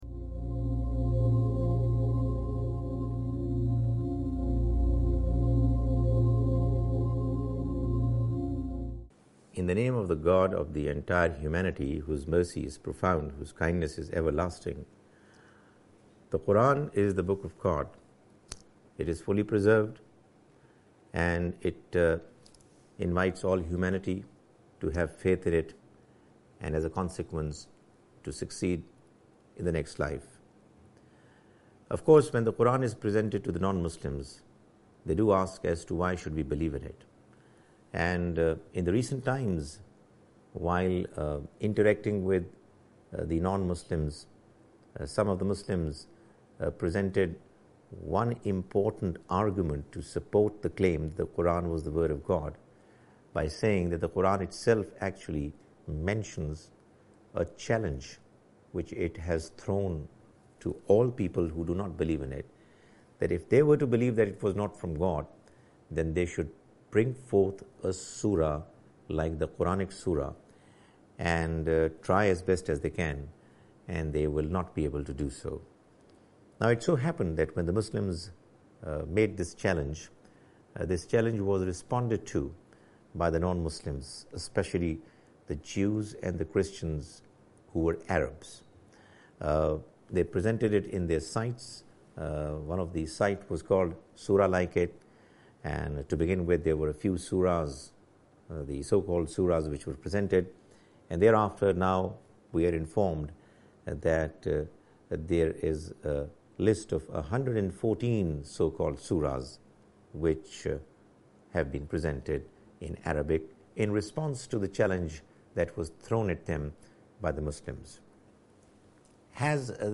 Associate Speakers